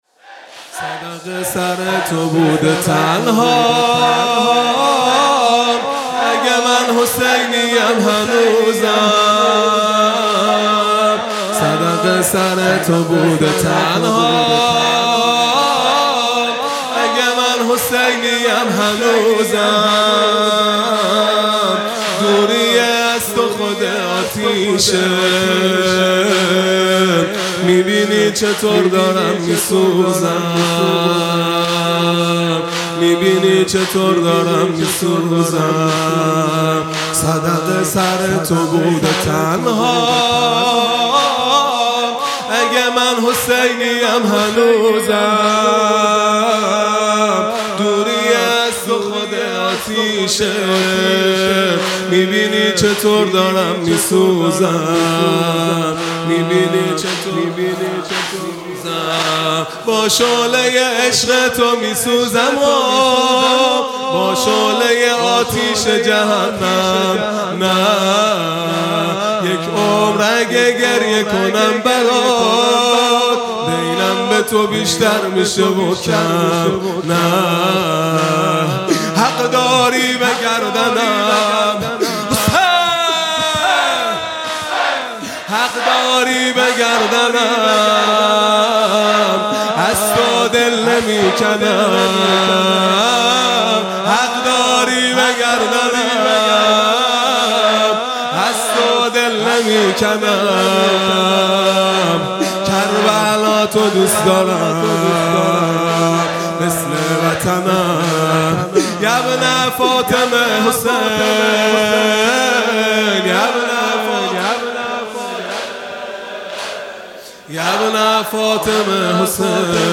محرم ۱۴۴۴ | شب چهارم